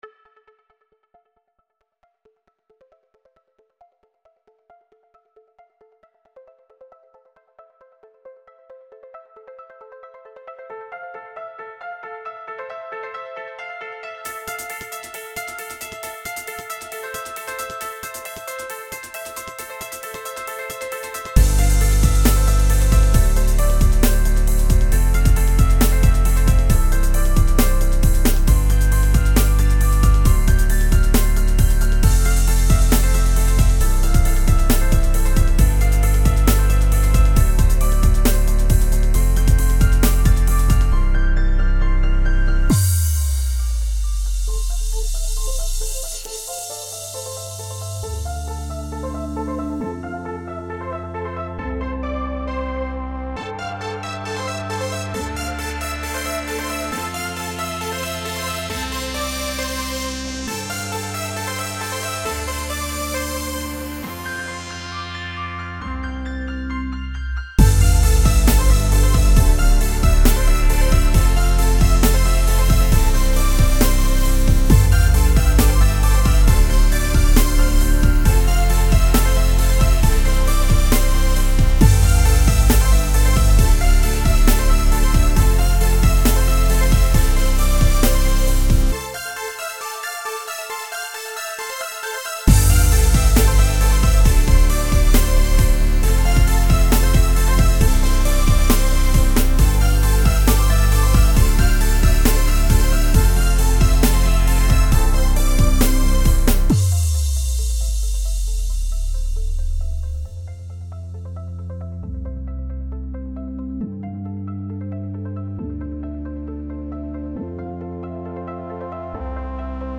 It flows smoother, and has more of a progressive feel.
This is a hurricane at high volume~!